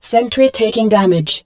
marine_sentrytakingdamage1.wav